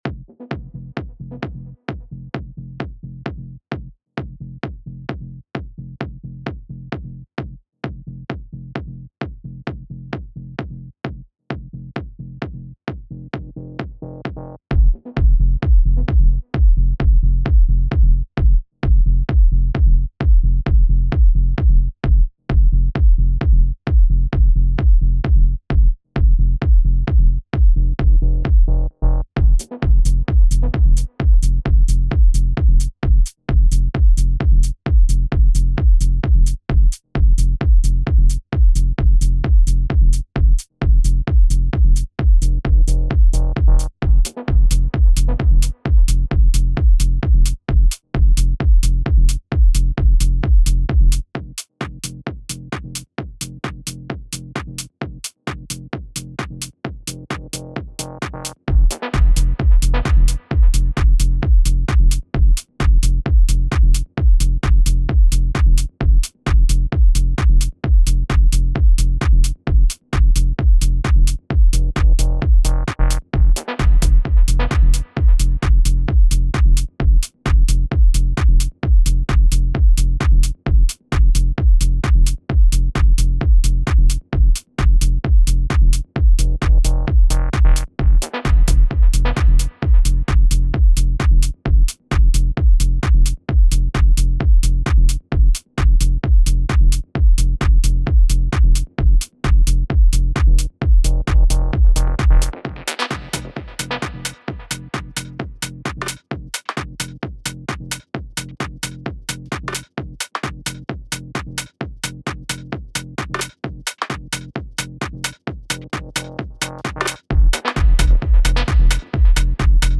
I like those laid back percs, got a groove going…lets see…
Add more stuff that is swung AF and keep that kick as thick as possible without overdoing it.